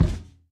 Minecraft Version Minecraft Version snapshot Latest Release | Latest Snapshot snapshot / assets / minecraft / sounds / mob / irongolem / walk1.ogg Compare With Compare With Latest Release | Latest Snapshot
walk1.ogg